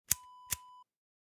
Zippo strike sound effect .wav
Description: The sound of striking a zippo lighter
Properties: 48.000 kHz 24-bit Stereo
A beep sound is embedded in the audio preview file but it is not present in the high resolution downloadable wav file.
Keywords: zippo, cigarette, lighter, fire, strike, ignition
zippo-strike-preview-1.mp3